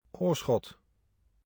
udtale ?) er en kommune og en by i den sydlige provins Noord-Brabant i Nederlandene.